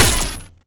etfx_shoot_lightning2.wav